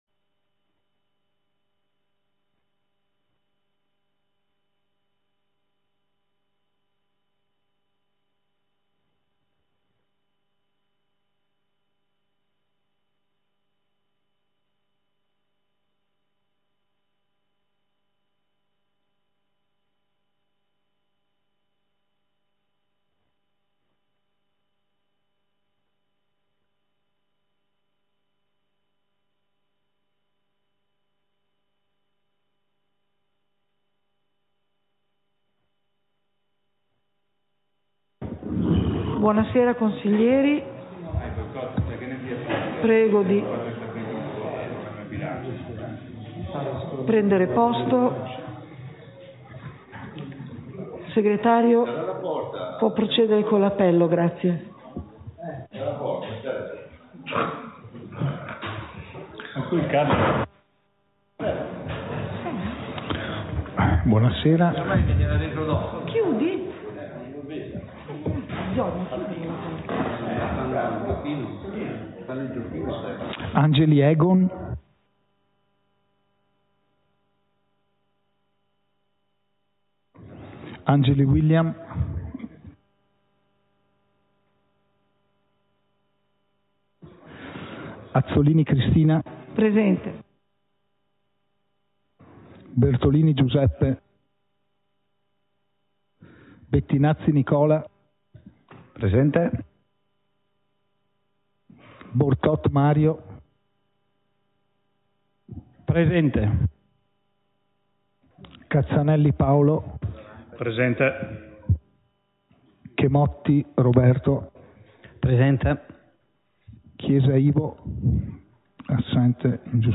Seduta del consiglio comunale - 04.07.2023